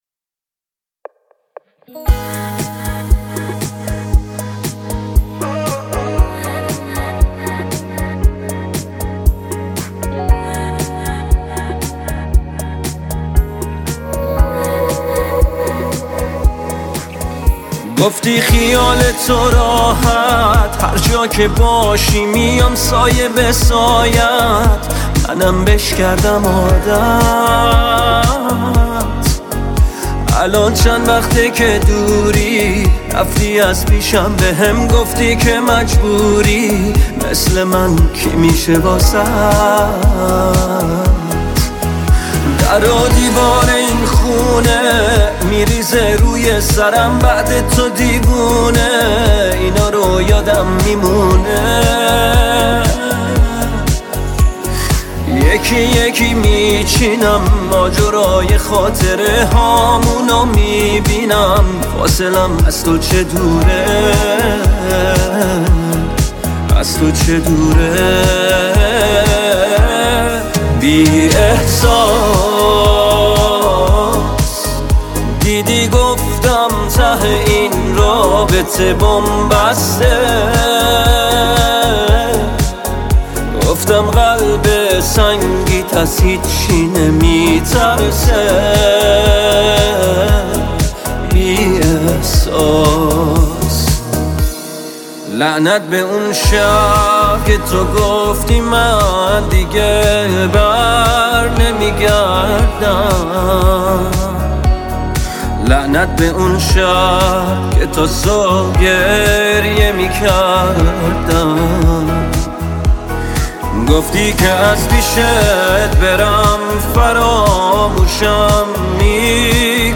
(Violin Version)